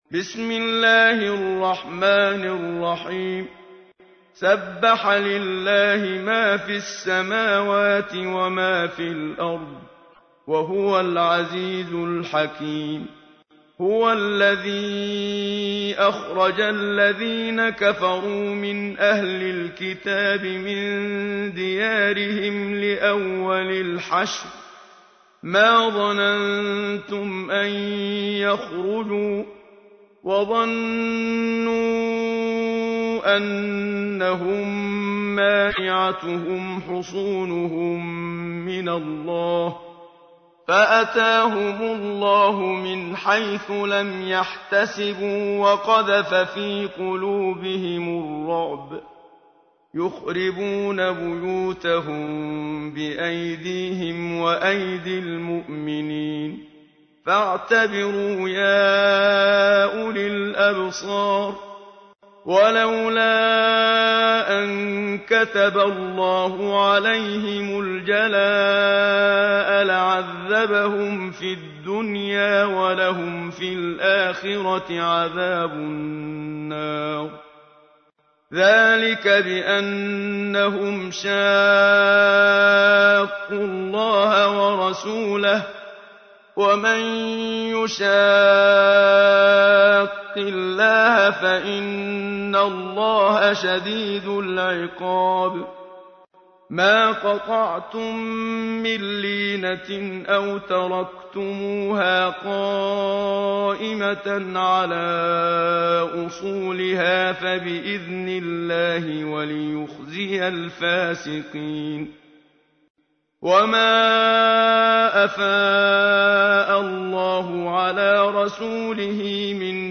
تحميل : 59. سورة الحشر / القارئ محمد صديق المنشاوي / القرآن الكريم / موقع يا حسين